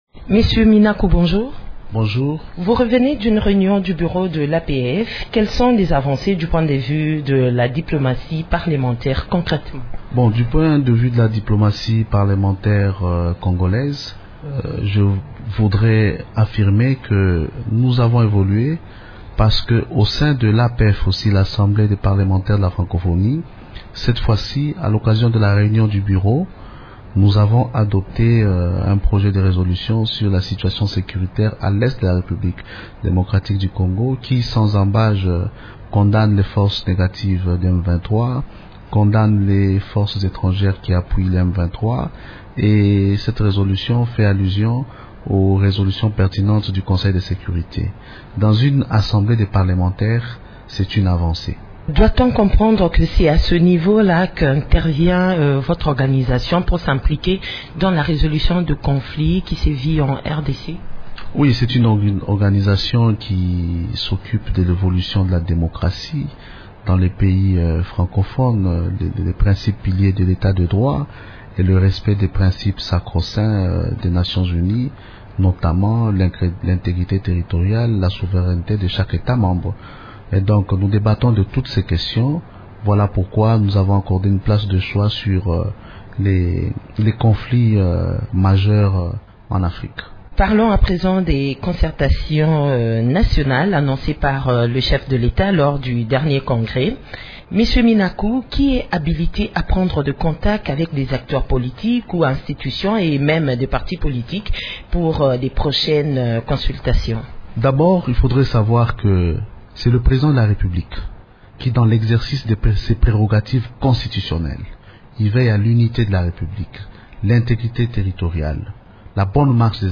Le président de l’Assemblée nationale de la RDC, Aubin Minaku, est l’invité de Radio Okapi ce mardi 12 février.